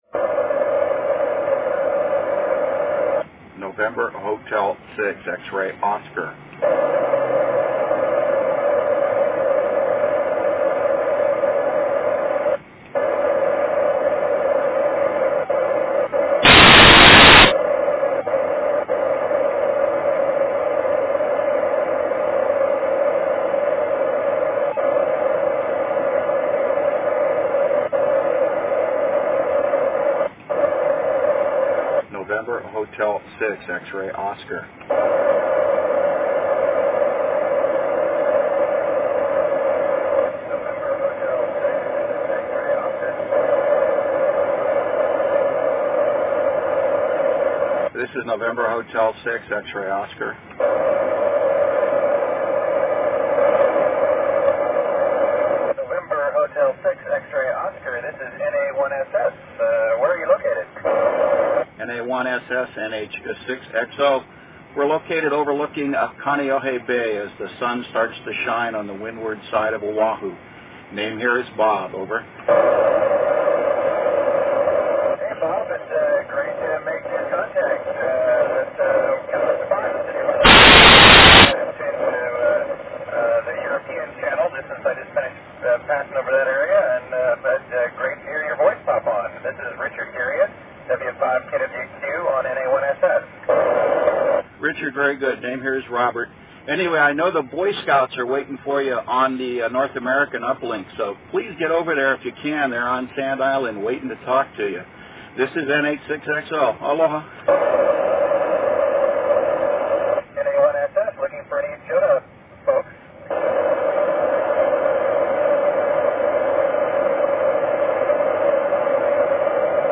Used an old cassette recorder's internal mike to record both speakers (uplink and downlink), my noises, and the dual band rig's female computer voice as ISS made contacts over Hawaii sunday morning.